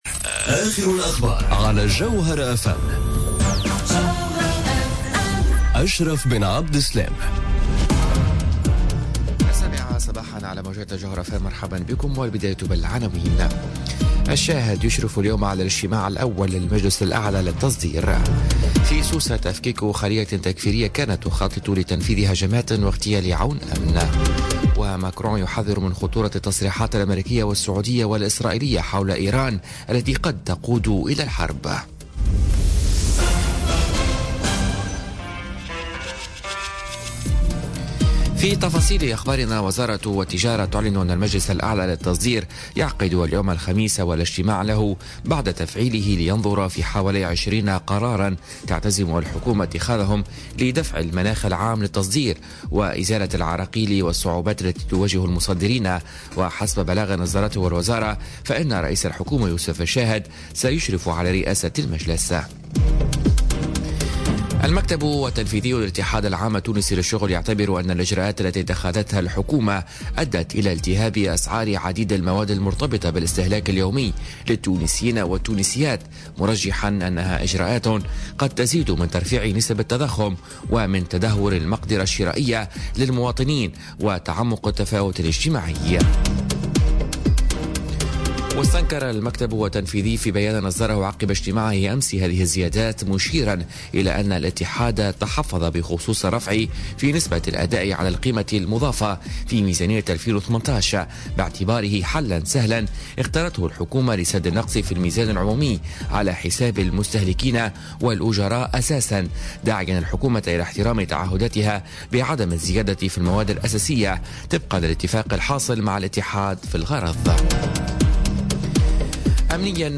نشرة أخبار السابعة صباحا ليوم الخميس 4 جانفي 2018